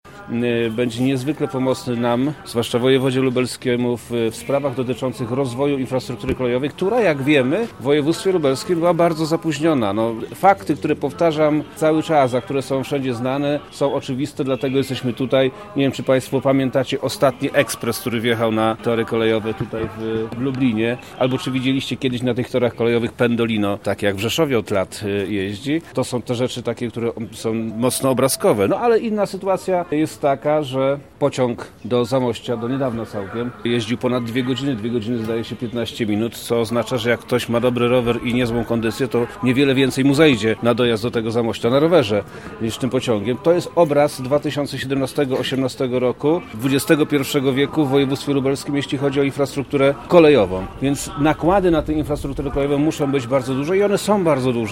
Przemysław Czarnek, wojewoda lubelski